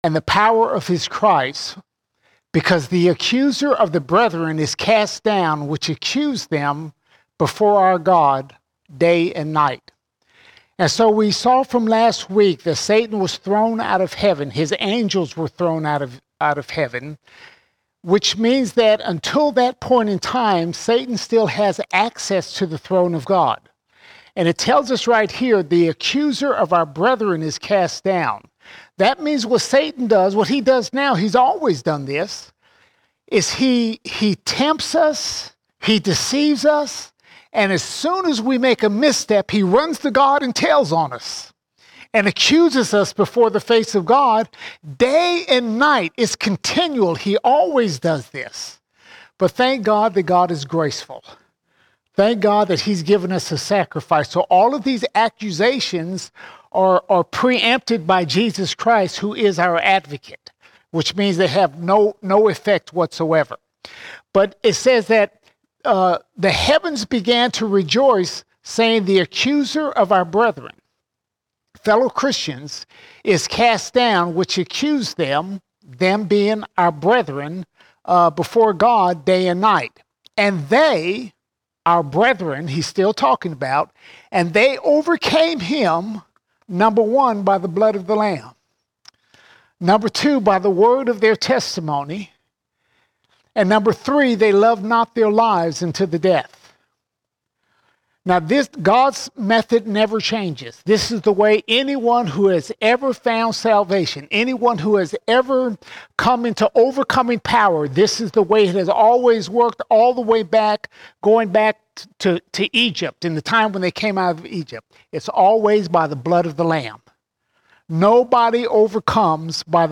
17 October 2024 Series: Revelation All Sermons Revelation 12:10 to 13:15 Revelation 12:10 to 13:15 We see the beast known as the antichrist and the false prophet.